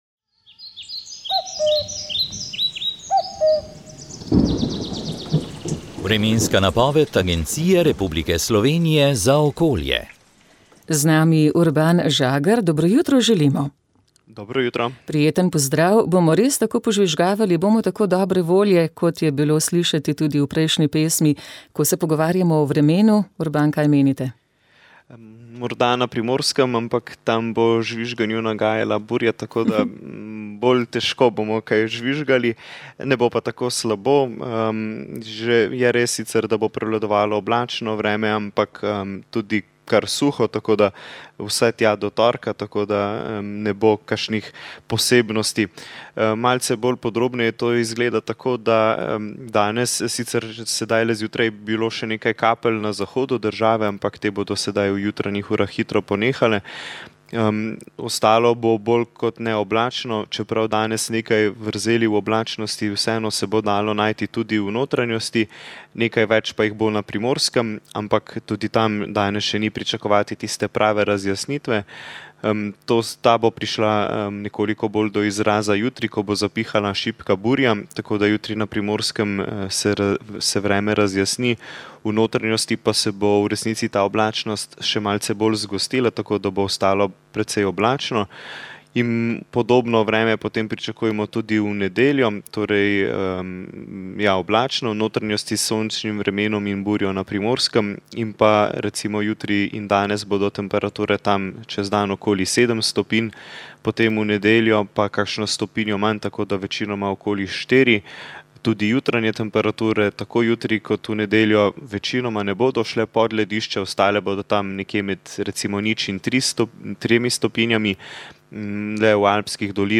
Sveta maša
Sv. maša iz cerkve Marijinega oznanjenja na Tromostovju v Ljubljani 4. 12.